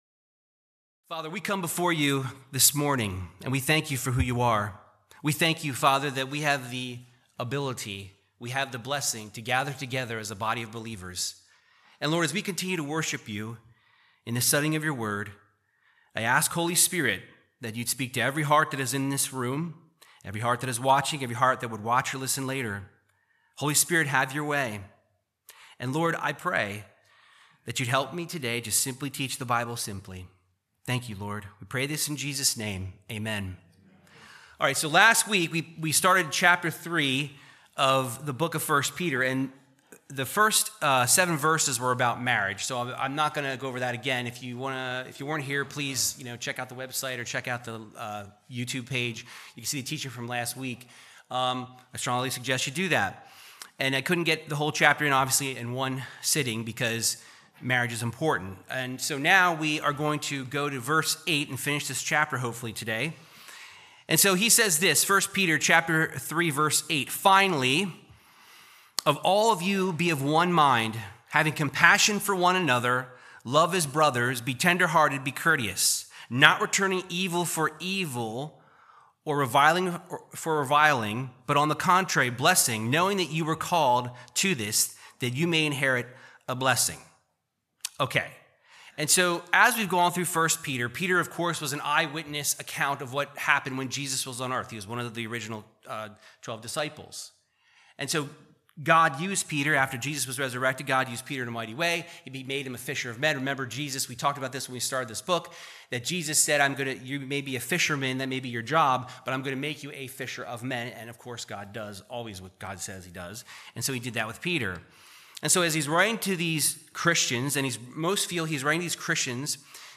Verse by verse Bible Teaching of 1 Peter 3:8-22 discussing a Christians relationship with others and suffering for doing good.